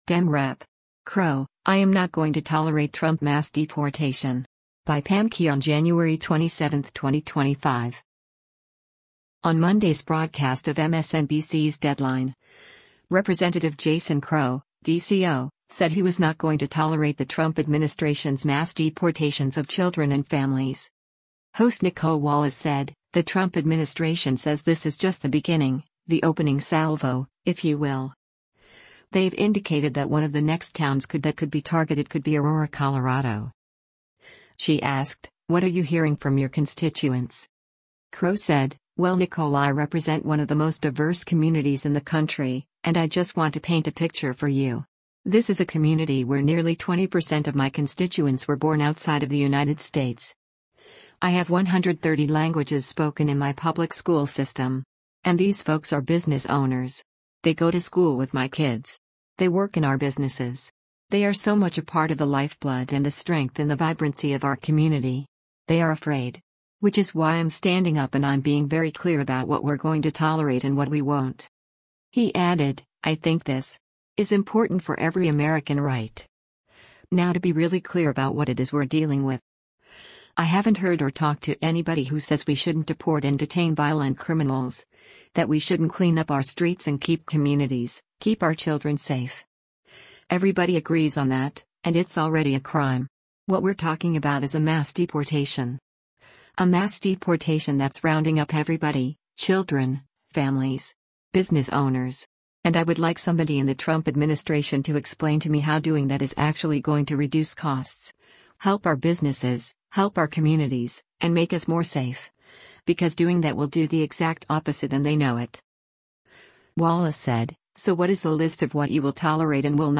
On Monday’s broadcast of MSNBC’s “Deadline,” Rep. Jason Crow (D-CO) said he was not going to tolerate the Trump administration’s mass deportations of children and families.